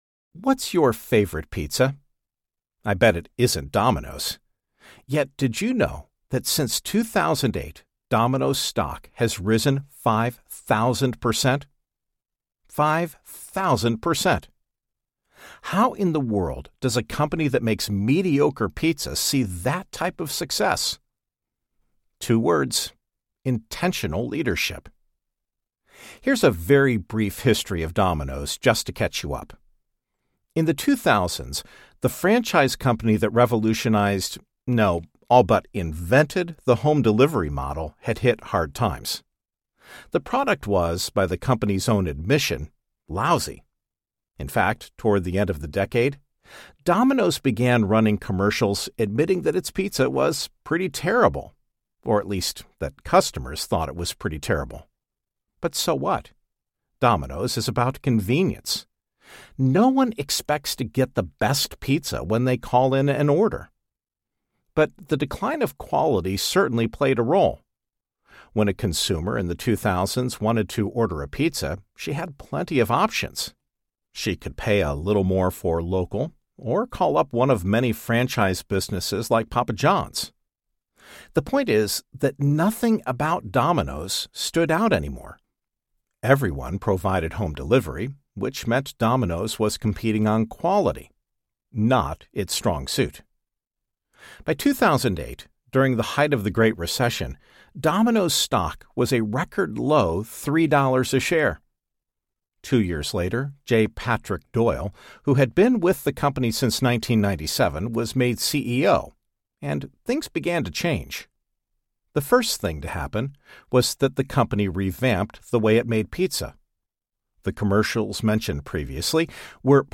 The Intention Imperative Audiobook
Narrator